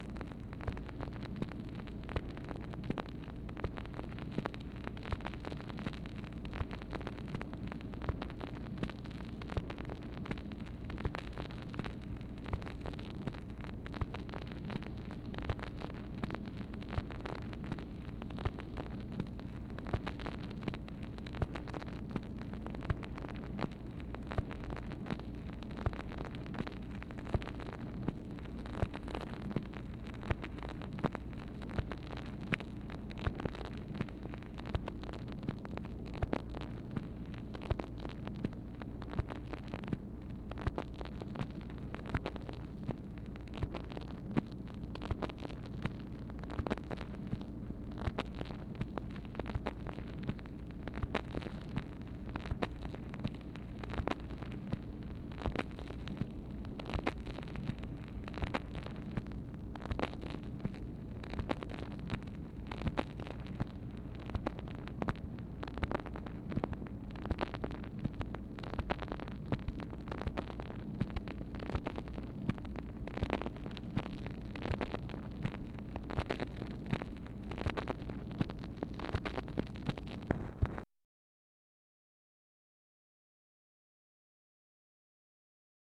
MACHINE NOISE, March 28, 1964
Secret White House Tapes | Lyndon B. Johnson Presidency